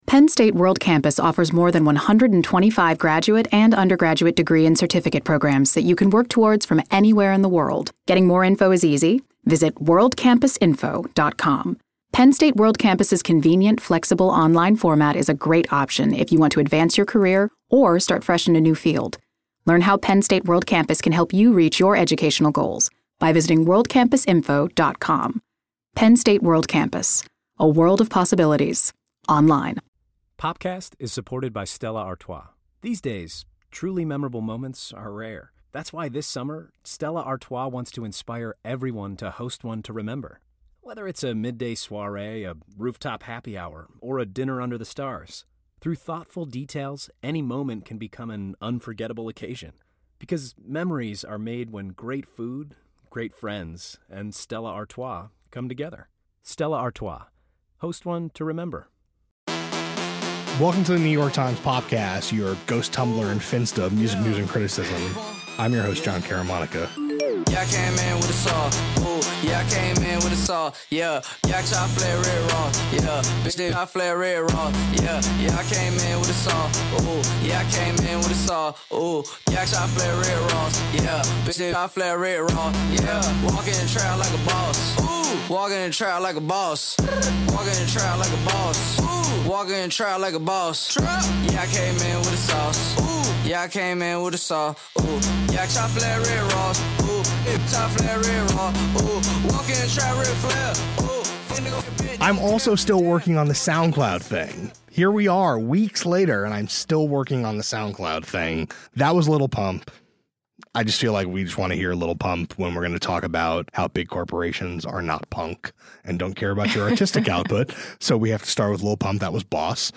If the music-streaming service disappeared, a culture might vanish too. A conversation about where the tech and culture worlds collide.